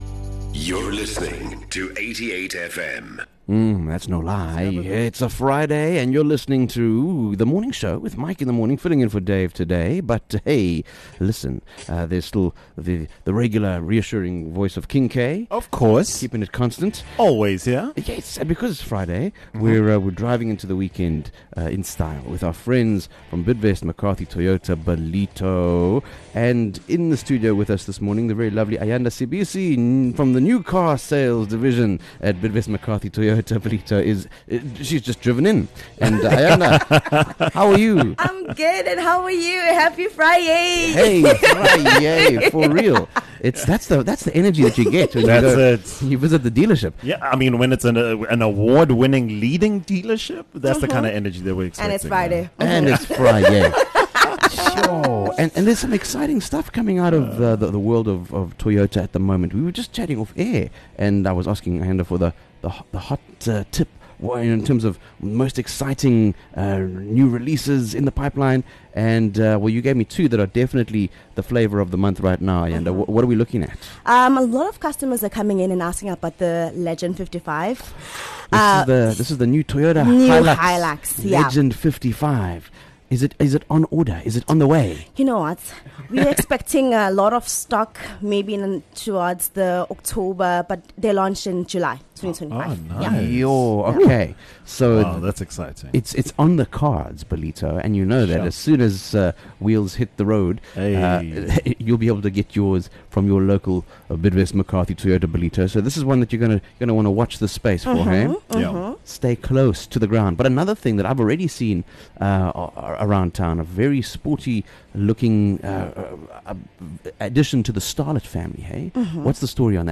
9 May Driving Excellence: A Morning Chat with Bidvest McCarthy Toyota Ballito